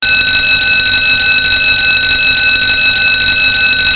firealarm2.wav